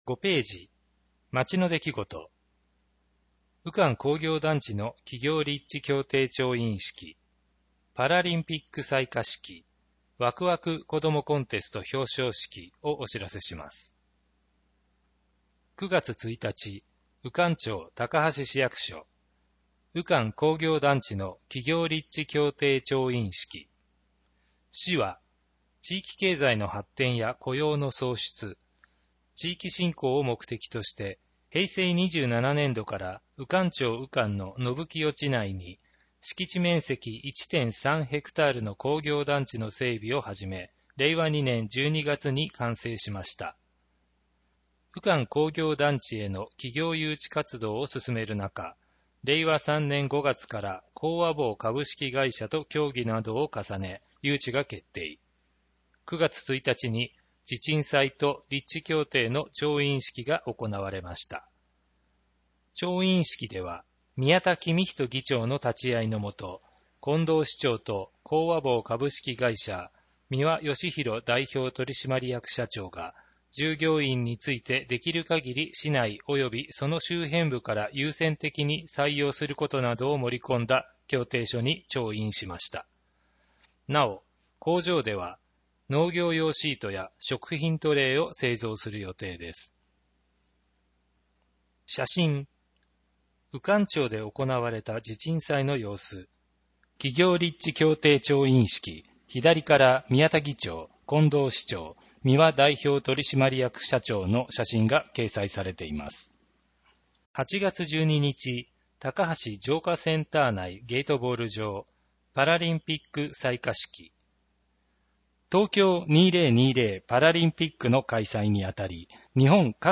声の広報　広報たかはし9月号（203）